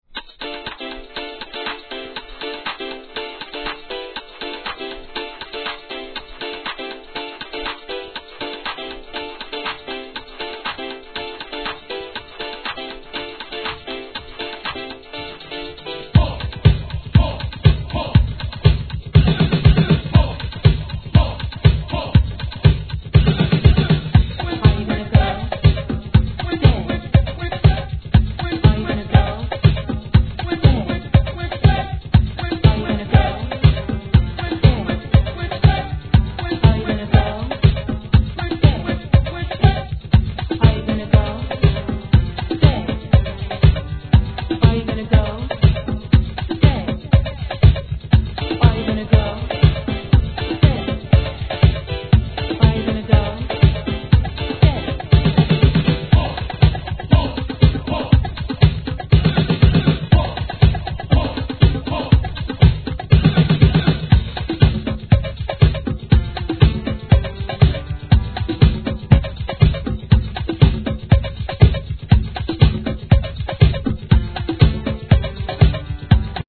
HIP HOP/R&B
ダンスBEAT物。